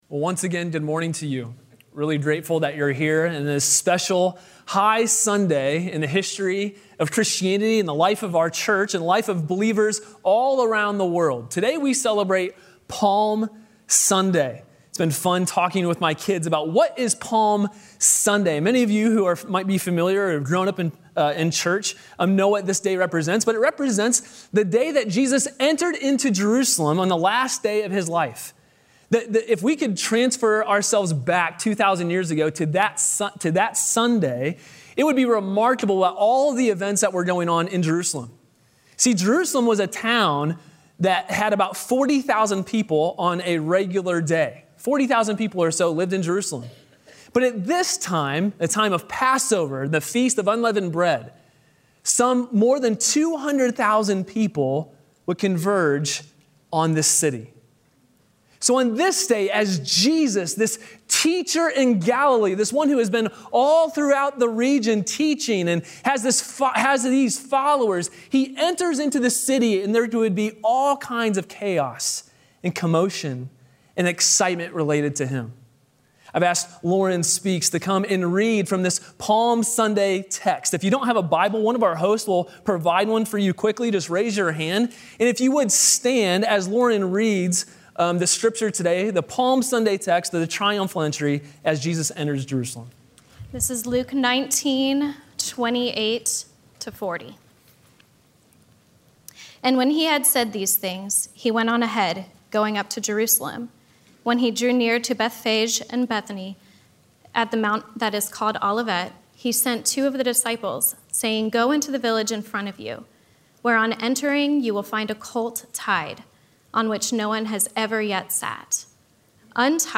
A sermon from the series "The Great Reversal."